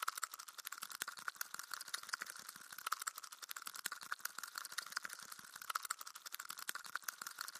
Bug Movement